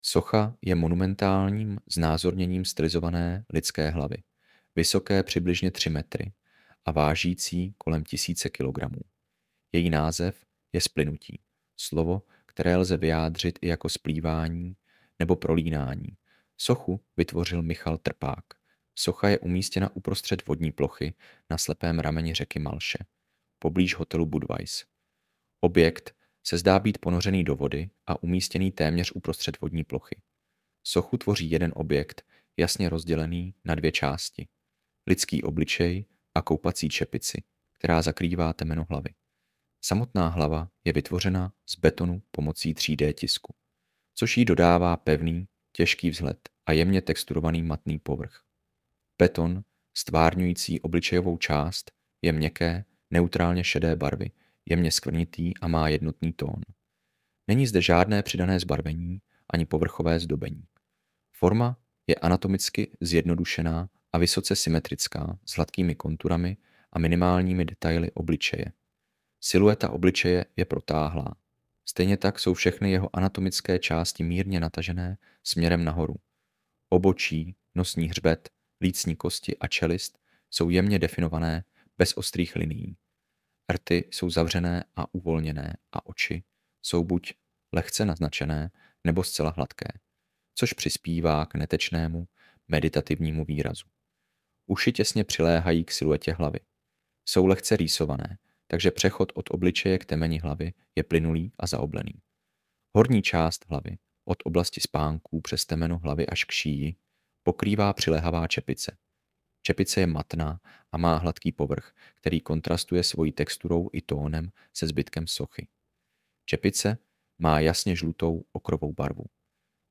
AUDIOPOPIS